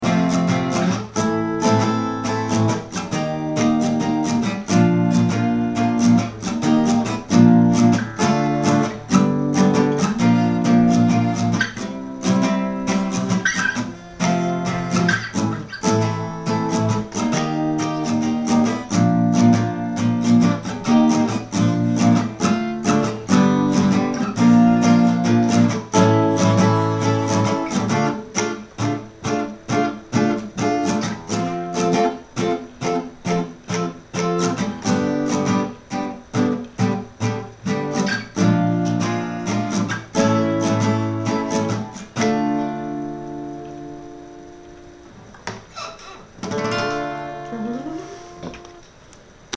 prospective chorus twice, into verse